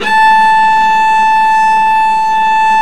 Index of /90_sSampleCDs/Roland - String Master Series/STR_Vc Marc&Harm/STR_Vc Harmonics